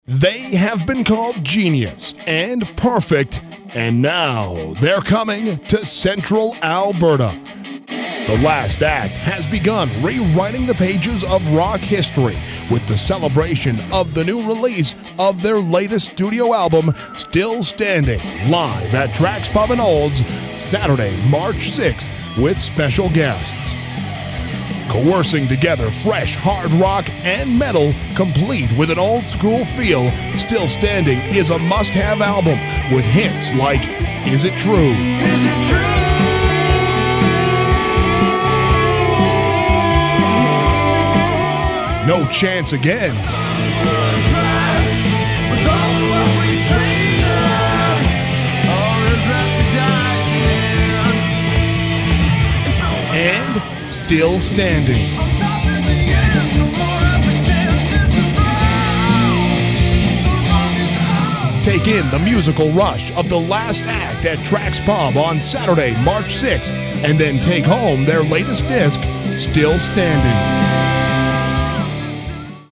The disc is on schedule for release and the ads have started for the Olds gig, March 6, 2010 @ Tracks Pub! The commercials have started all over central Alberta, from just south of Edmonton to just north of Calgary on Rock 104 FM…quite the radius!